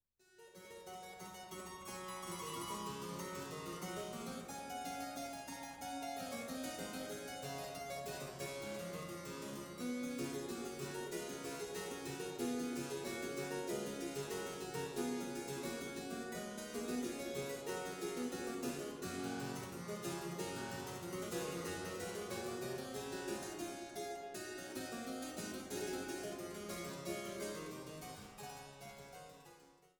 Schlosskirche Altenburg
Cembalo